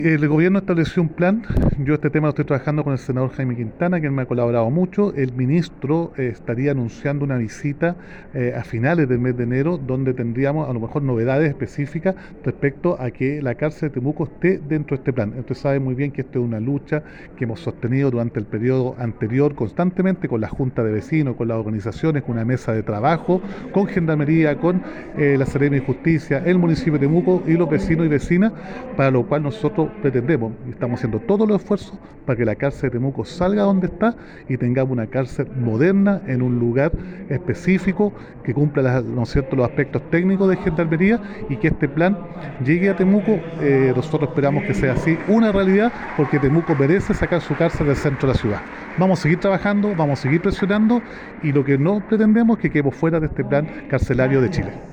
Roberto-Neira-alcalde-de-Temuco.mp3